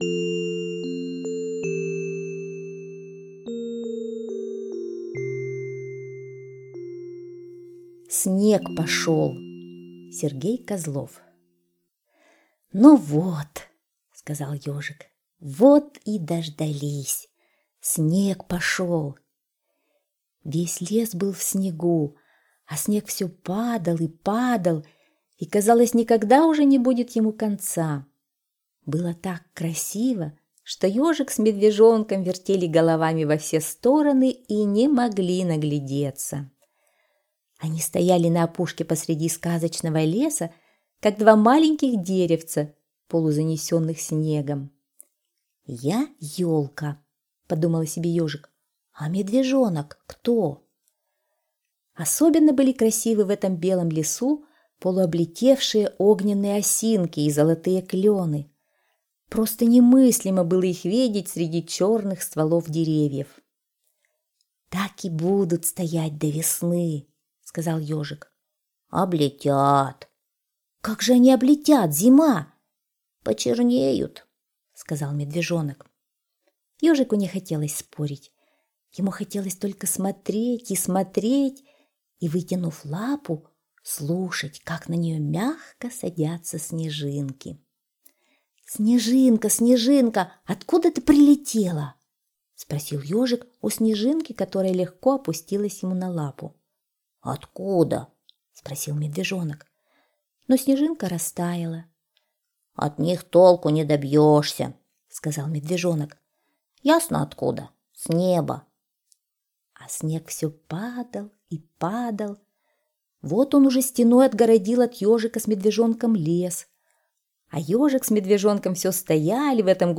Слушайте Снег пошел - аудиосказка Козлова С.Г. Сказка про то, как в лесу выпал первый снег.